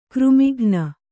♪ křmighna